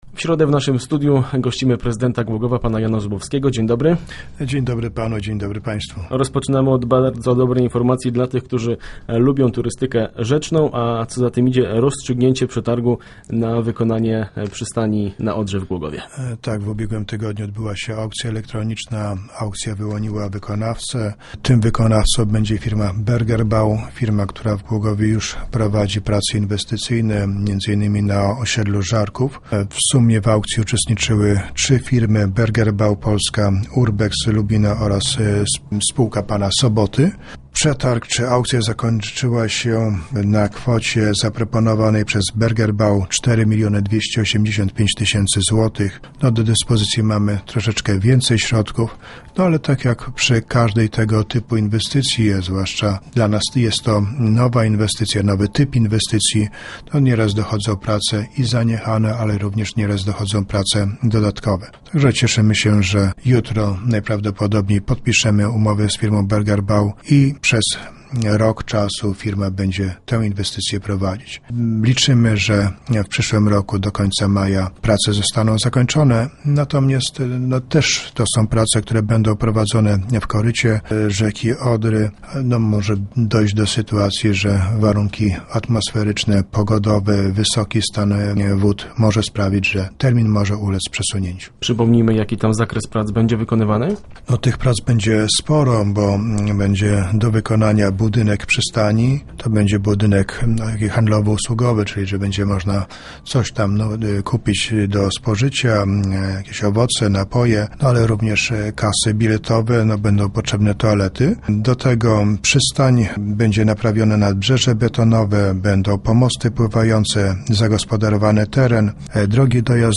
Mówił o tym w Rozmowach Elki prezydent Jan Zubowski.